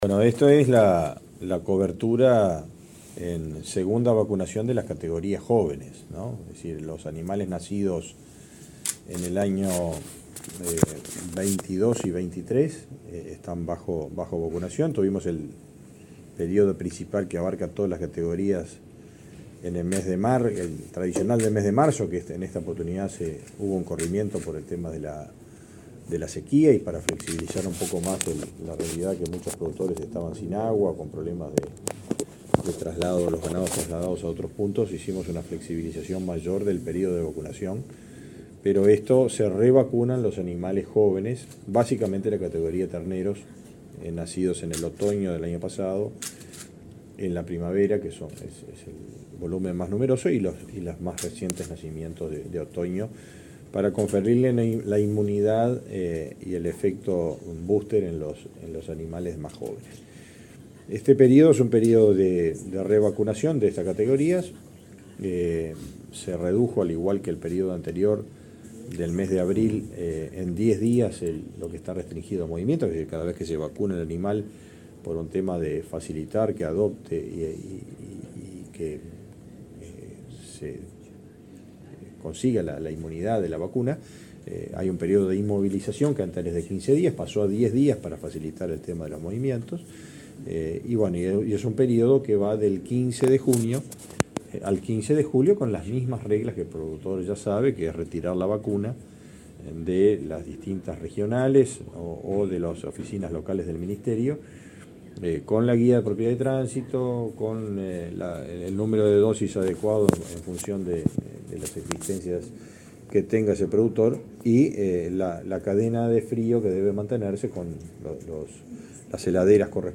Declaraciones del ministro de Ganadería, Fernando Mattos
Declaraciones del ministro de Ganadería, Fernando Mattos 14/06/2023 Compartir Facebook X Copiar enlace WhatsApp LinkedIn El Ministerio de Ganadería informó, en una conferencia de prensa, acerca de la nueva etapa de vacunación contra la fiebre aftosa a bovinos nacidos en los años 2022 y 2023. Luego el titular de la cartera, Fernando Mattos, dialogó con la prensa.